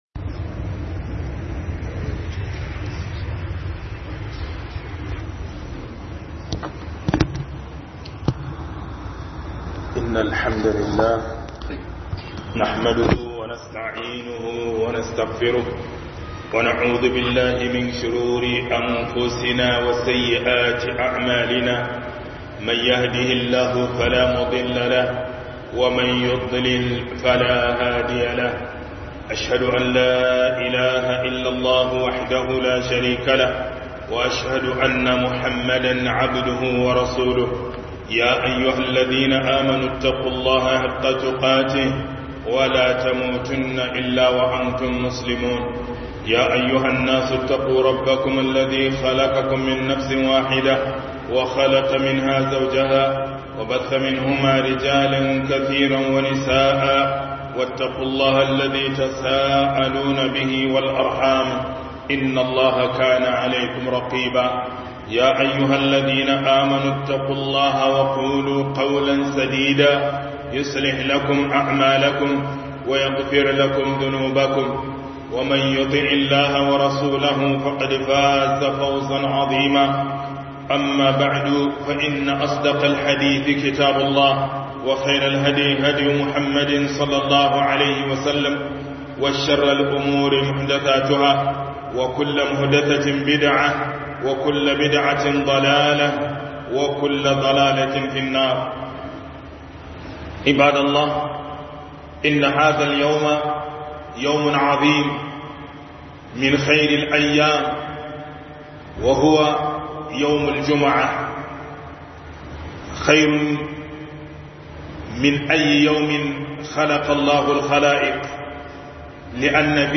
Hudubar Juma'a